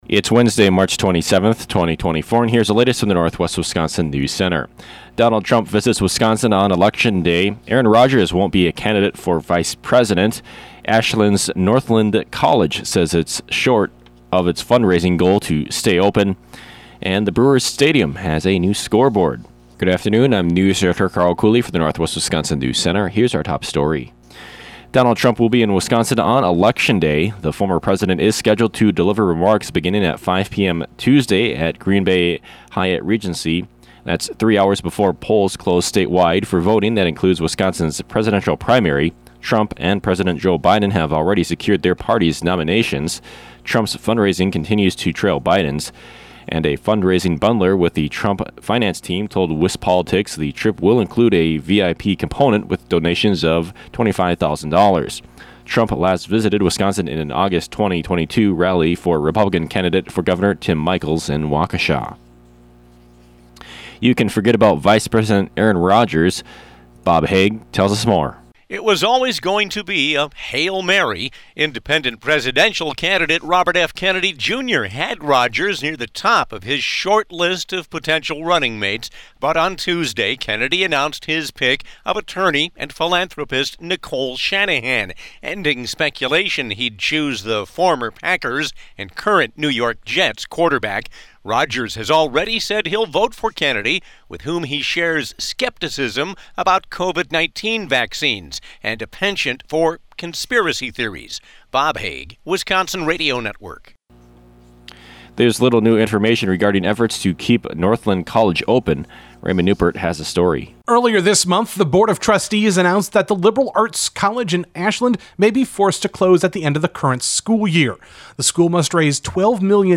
PM NEWSCAST – Wednesday, March 27, 2024 | Northwest Builders, Inc.